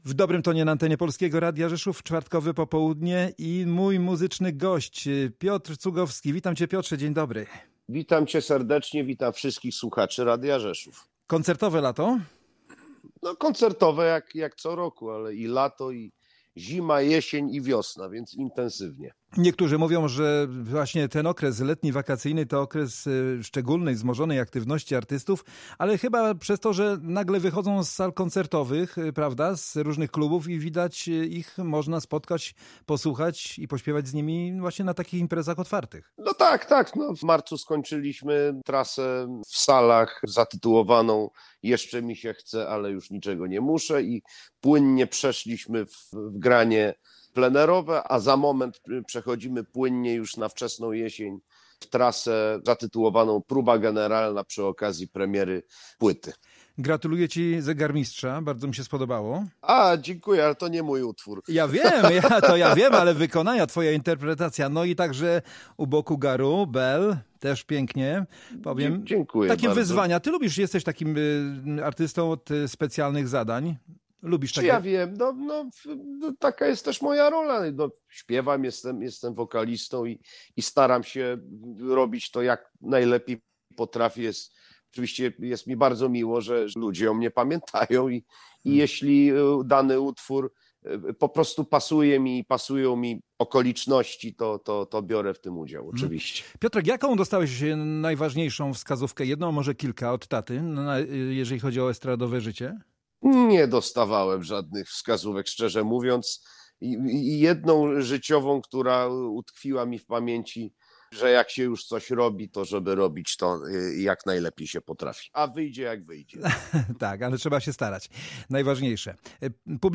Piotr Cugowski był gościem audycji „W dobrym tonie”.